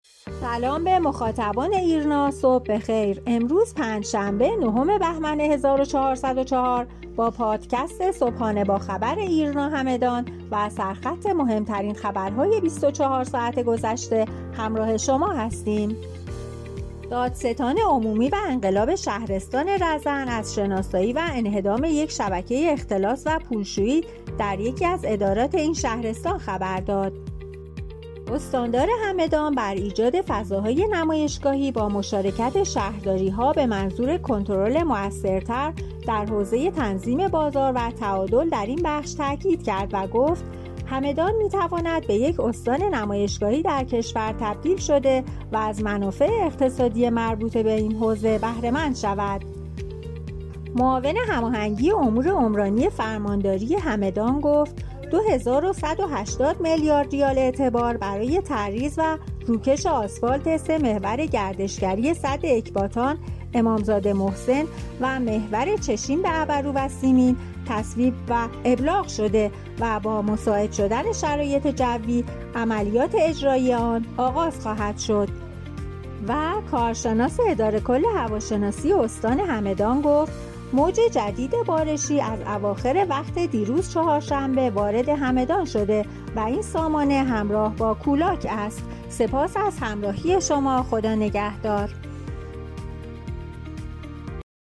خبرنامه صوتی (پادکست) صبحانه با خبر ایرنا همدان را هر روز ساعت هشت صبح دنبال کنید.